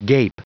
Prononciation du mot gape en anglais (fichier audio)
Prononciation du mot : gape